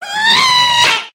scream2.mp3